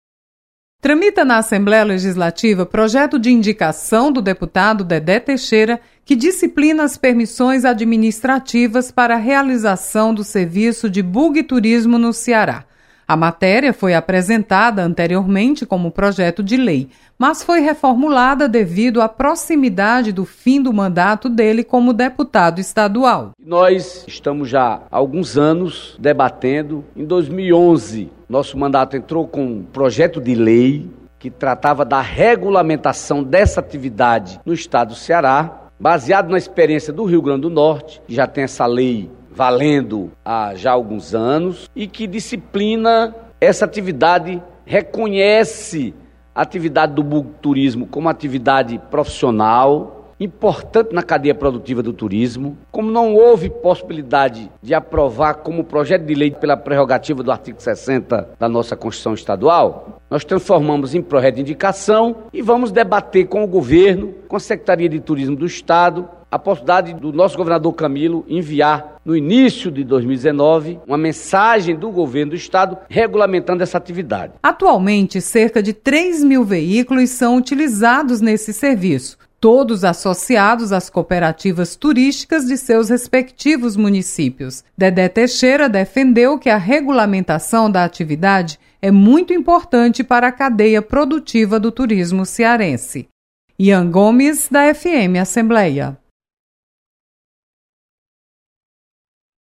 Projeto regulamenta serviço de Buggy. Repórter